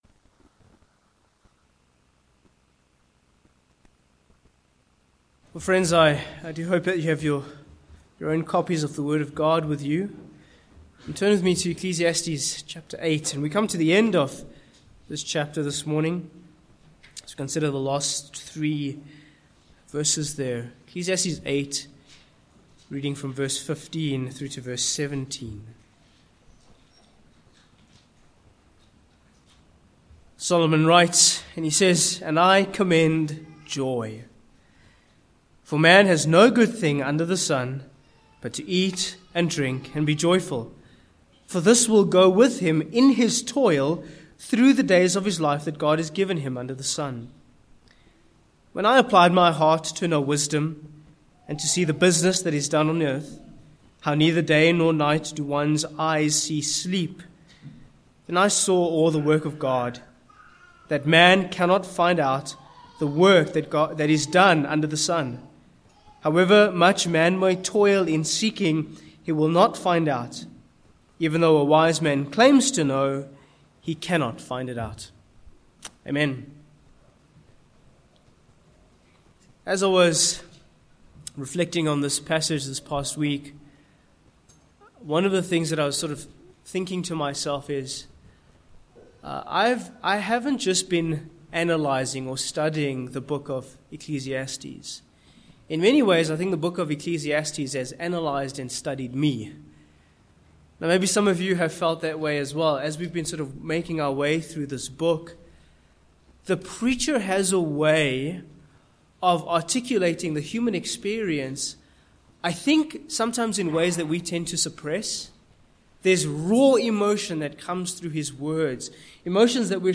Ecclesiastes 8:15-17 Service Type: Morning Passage